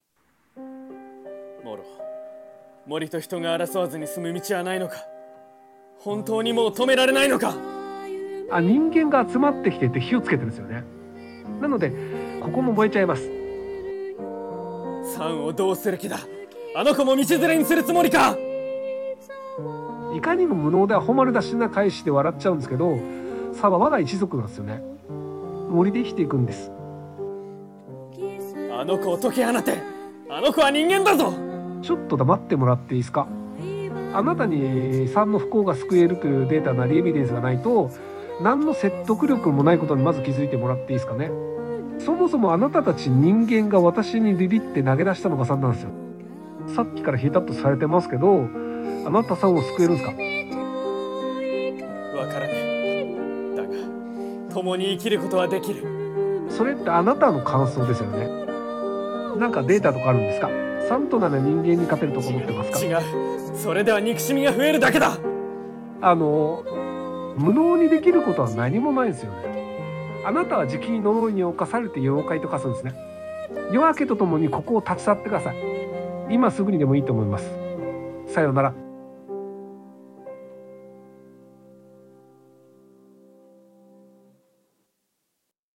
声劇｢もののけ姫｣ / アシタカ vs ひろゆき